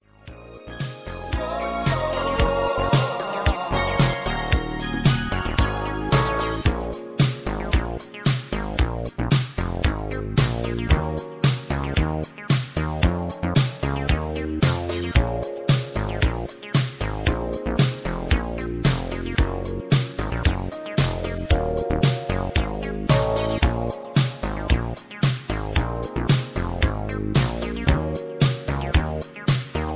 mp3 backing tracks